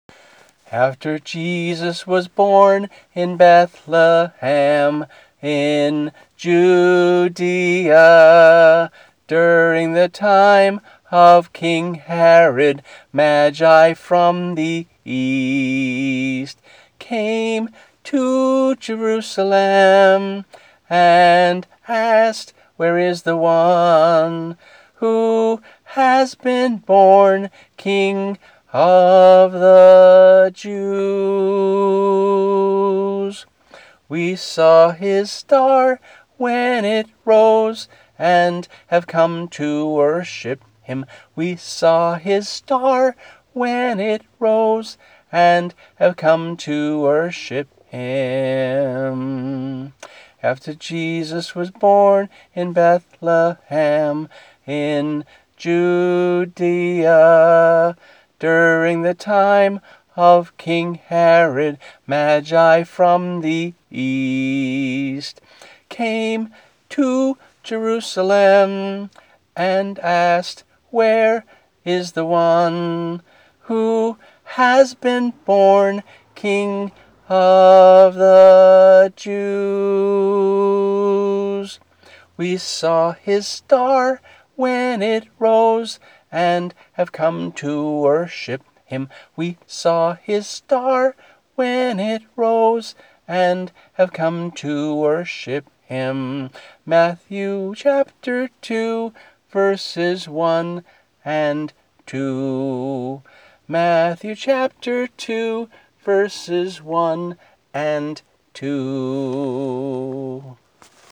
Scripture songs, based on some